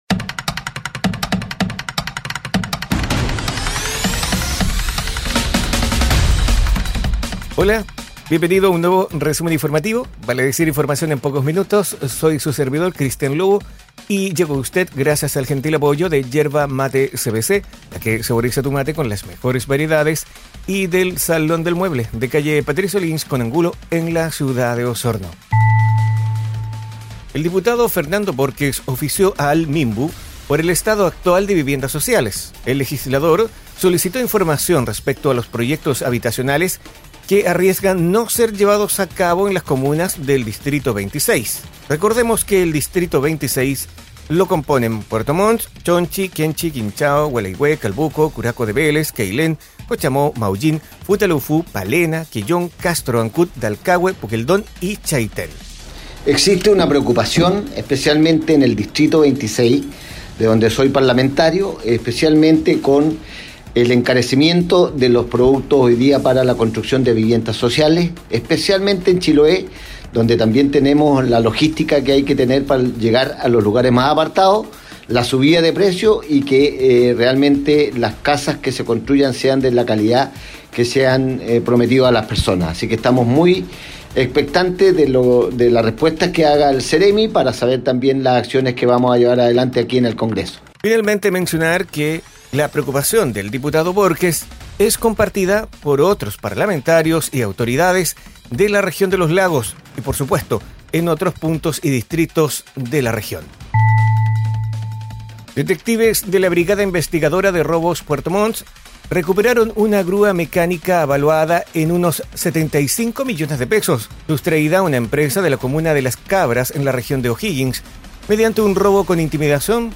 Resumen informativo es un audio podcast con una decena informaciones en pocos minutos, enfocadas en la Región de Los Lagos